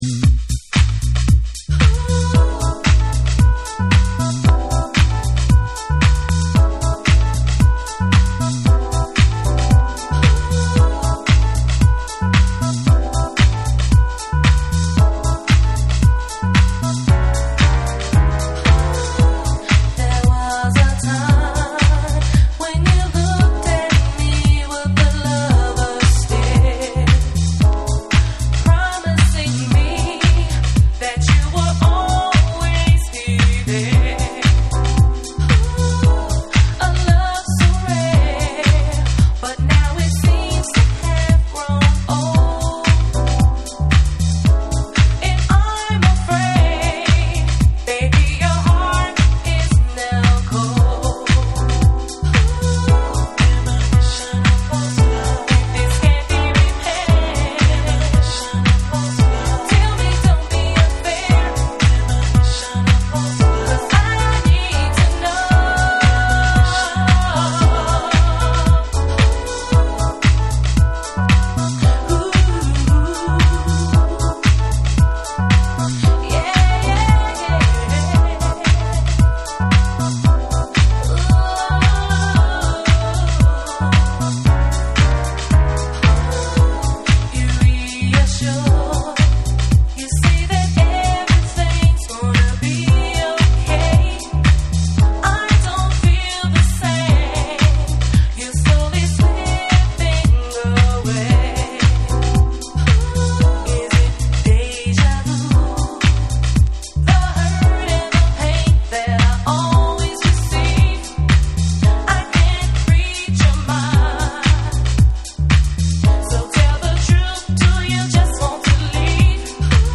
女性ヴォーカル・ハウスのリミックス盤
TECHNO & HOUSE / ALL 840YEN